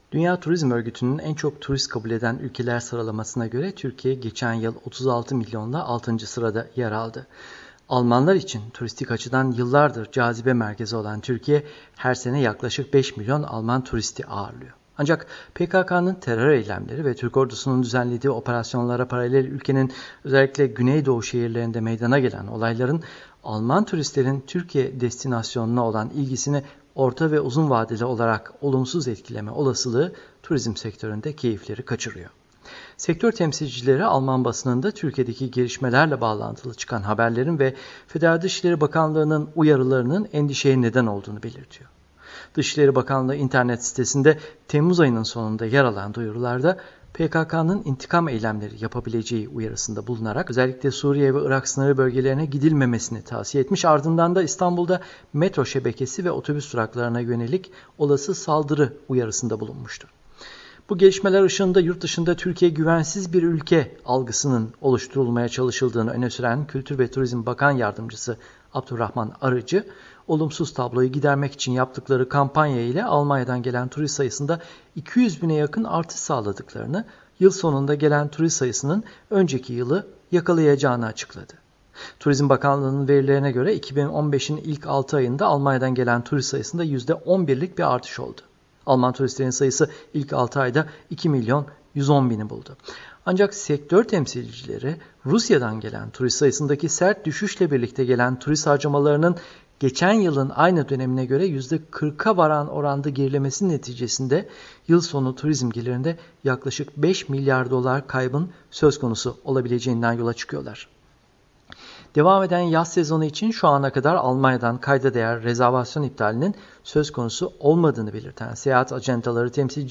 BERLİN —